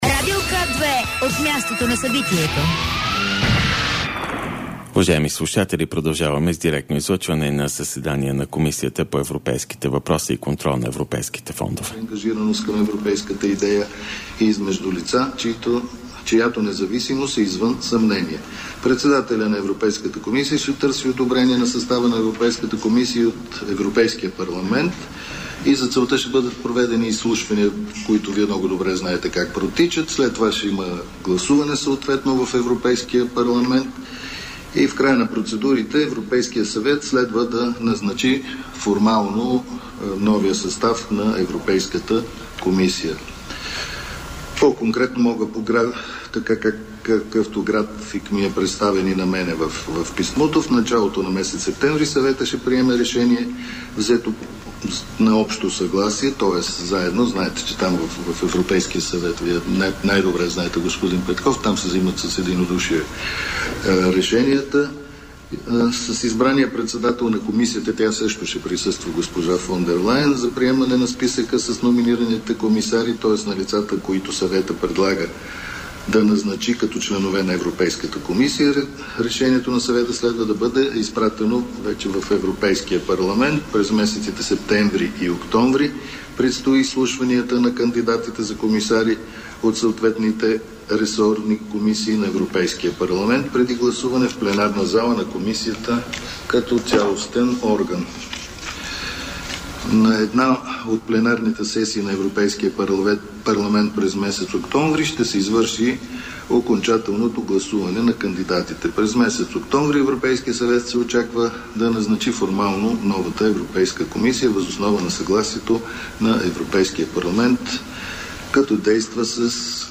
14.50 - Брифинг на служебния премиер Димитър Главчев за предложението на Георги Йорданов за орден Стара планина.  директно от мястото на събитието (пл. „Княз Александър I" №1, зала 3)
Директно от мястото на събитието